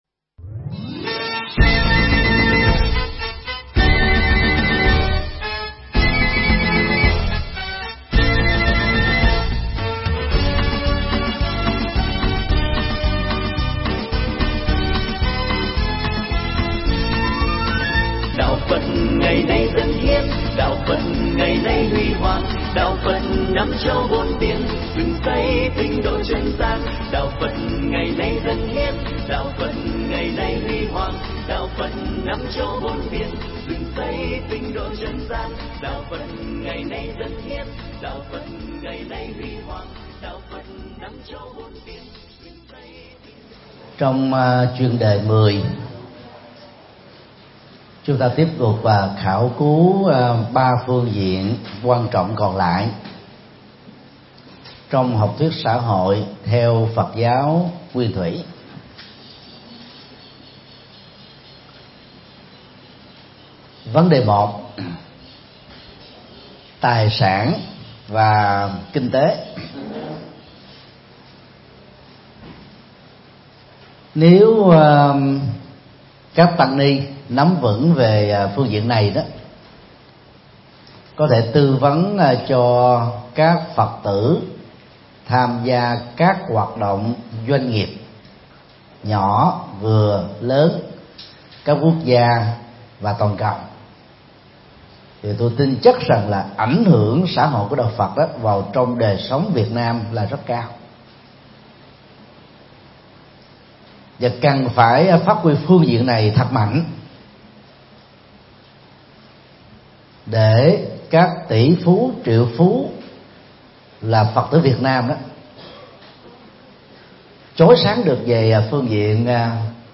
Mp3 Thuyết Pháp Xã Hội Theo Phật Giáo Nguyên Thủy (Tiếp Theo) – Thượng Tọa Thích Nhật Từ giảng tại HVPGVN TPHCM, ngày 11 tháng 7 năm 2018